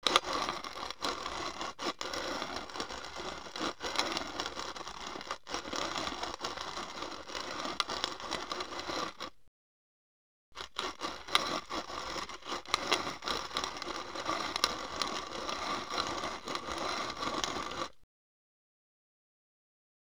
Coffee Grinder Hand Crank Sound
household